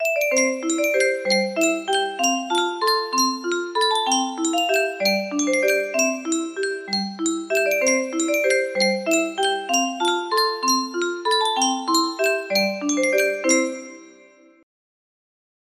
Yunsheng Music Box - Fair Harvard 1087 music box melody
Full range 60